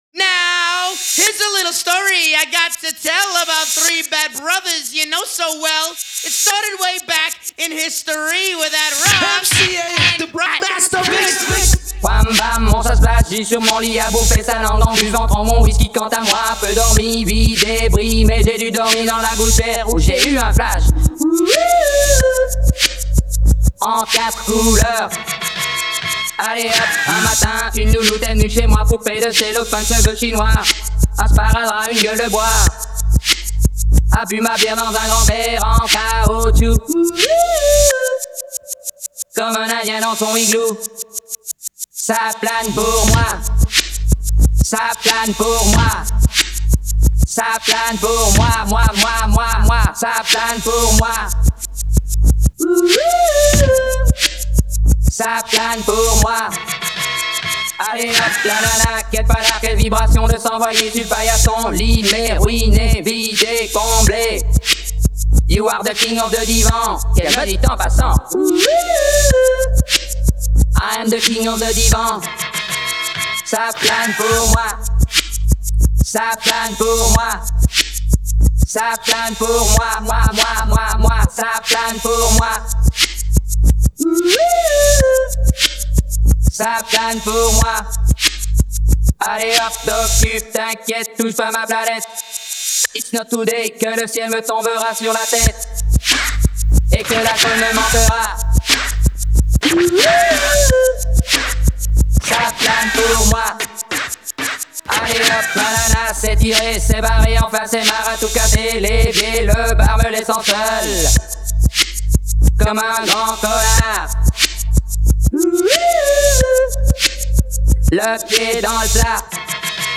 DIY Acapella
DIY Instrumental